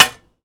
R - Foley 111.wav